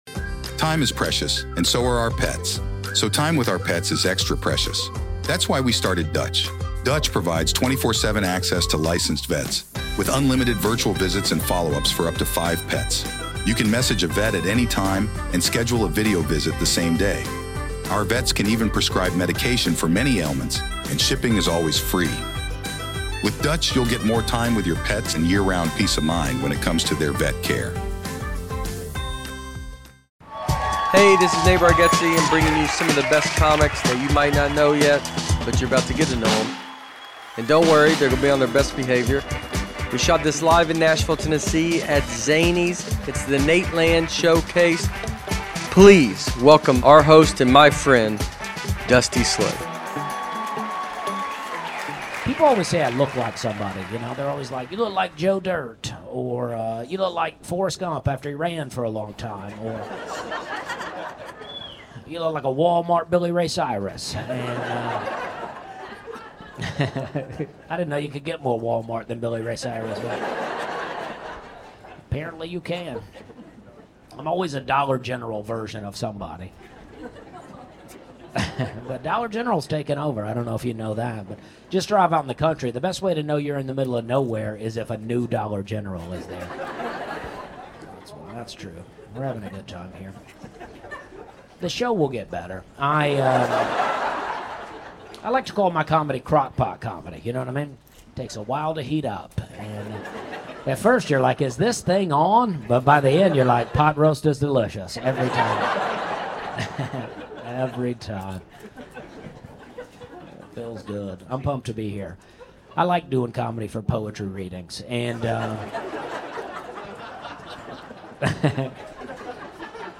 The Showcase features several of the best comics that you might not know yet, but you're about to get to to know 'em. Hosted by the members of The Nateland Podcast and directed by Nate Bargatze himself, The Showcase was recorded live at Zanies Comedy Club in Nashville, Tennessee. Standup comedy at its best.